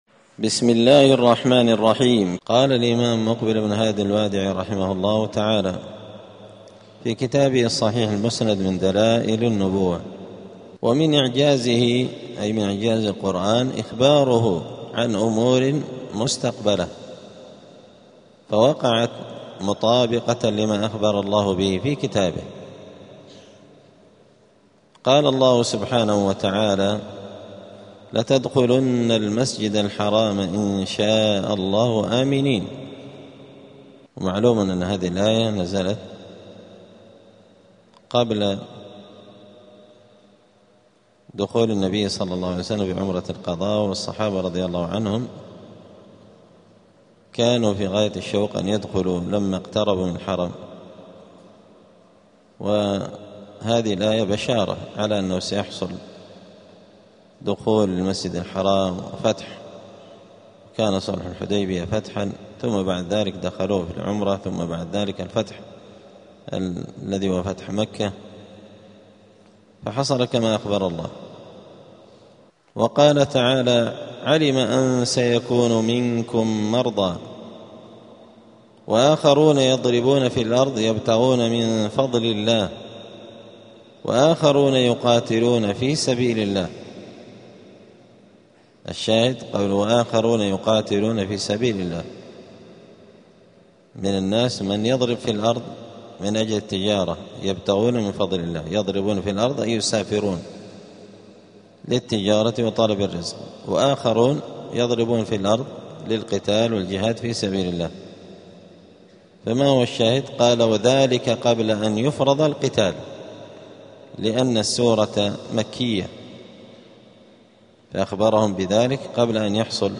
*الدرس الخامس (5) {فصل من إعجاز القرآن إخباره عن أمور مستقبلة}.*
دار الحديث السلفية بمسجد الفرقان قشن المهرة اليمن